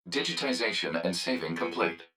042_Saving_Complete.wav